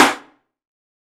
SNARE 043.wav